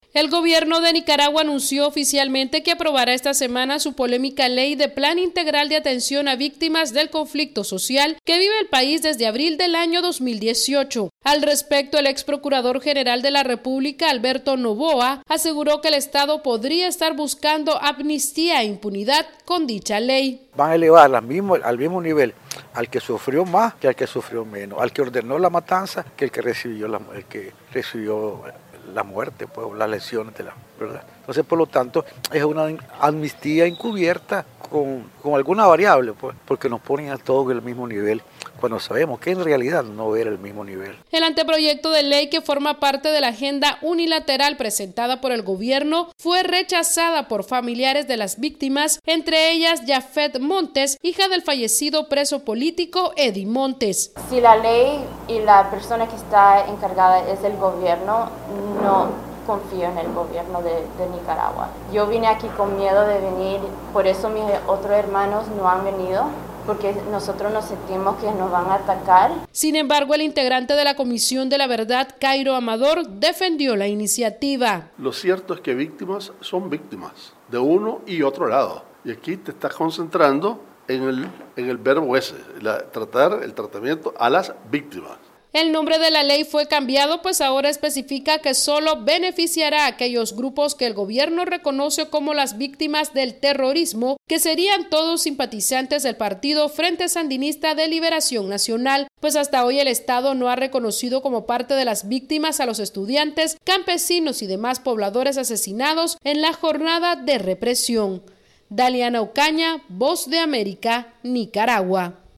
VOA: Informe desde Nicaragua